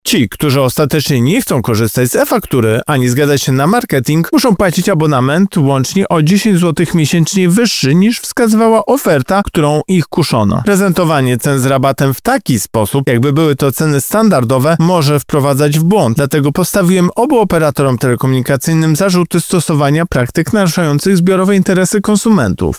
– mówi Tomasz Chróstny, prezes Urzędu Ochrony Konkurencji i Konsumentów.